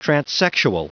Prononciation du mot transsexual en anglais (fichier audio)
transsexual.wav